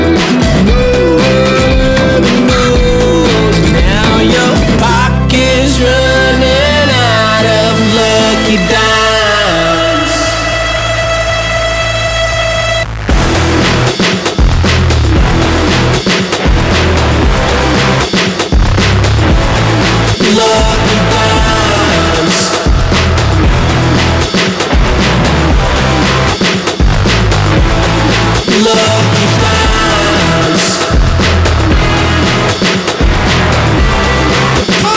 Нестандартные аккорды и неожиданные переходы трека
Жанр: Альтернатива